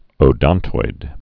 (ō-dŏntoid)